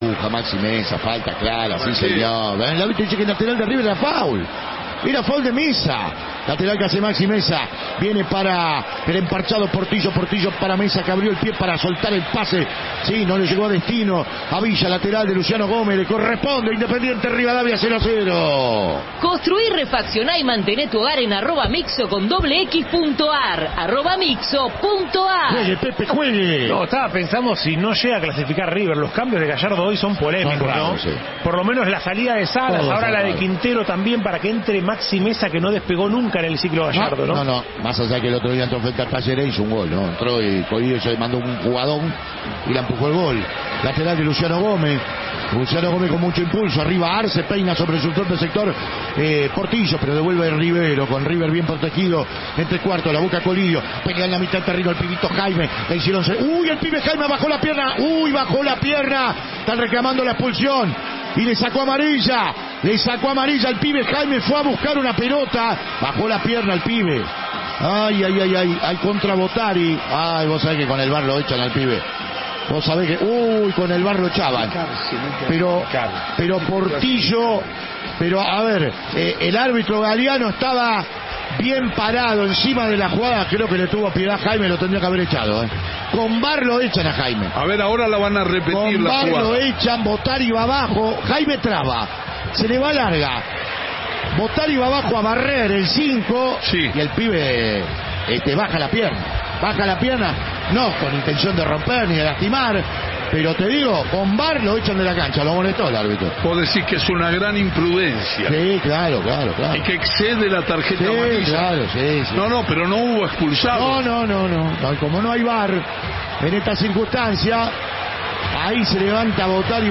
Además en sus grabaciones se escucha cada emisión completa, inclusive las partes musicales, cosa que yo al menos, no encontré en otros sitios. Esta vez, me encontré con que los primeros 54 minutos de la grabación reptoducen el final del partido Independiente de Mendoza/River Plate, que definían qué equipo pasaba a la final de la Copa Argentina.